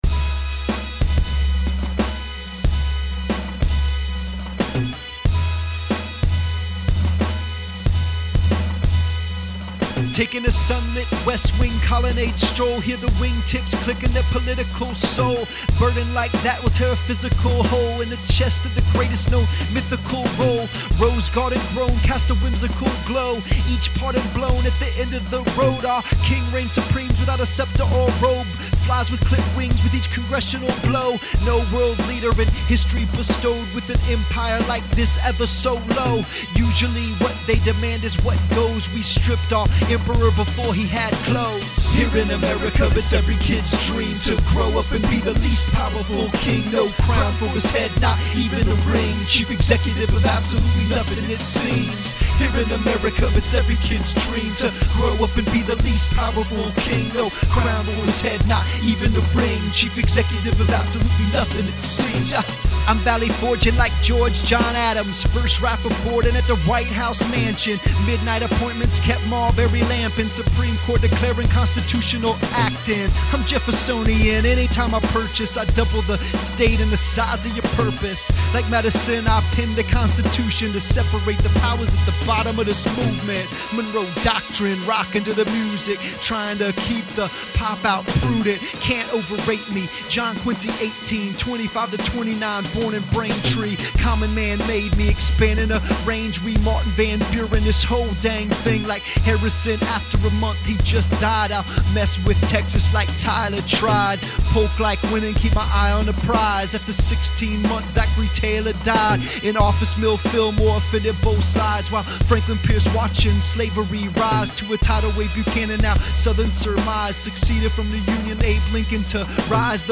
When truant students miss history class, they should just listen to some rap news…